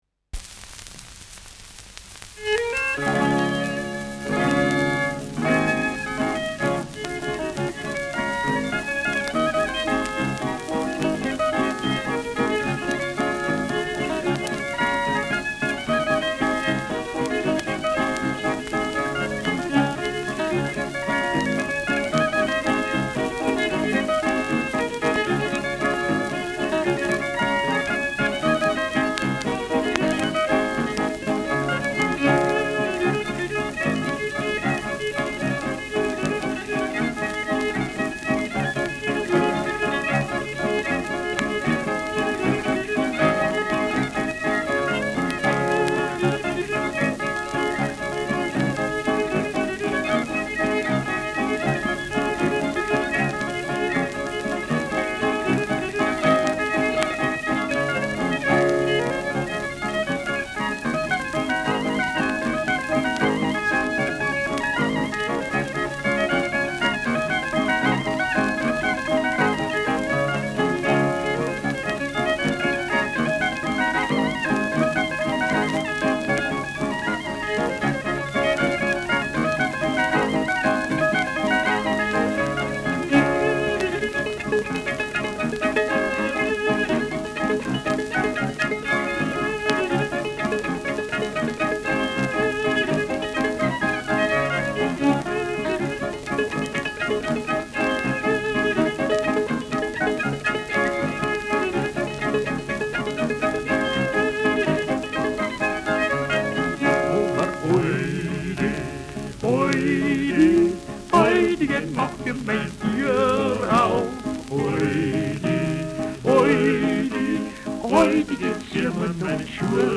Schellackplatte
leichtes Rauschen : leichtes Knistern : vereinzeltes Knacken
Stubenmusik* FVS-00016